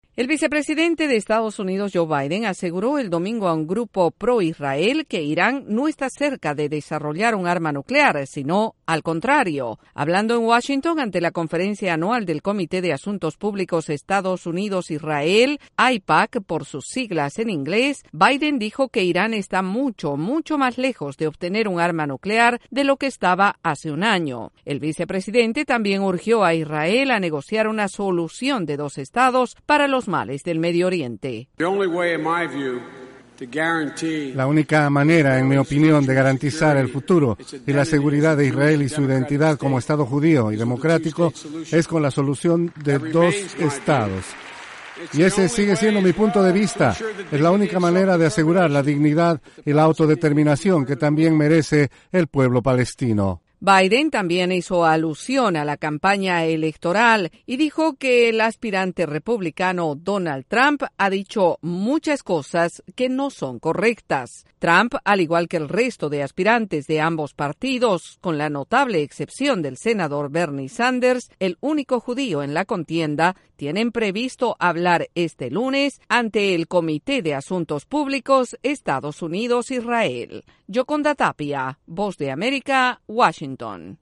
El vicepresidente Joe Biden expone sus puntos de vista sobre la seguridad en Israel en una conferencia sobre asuntos judíos.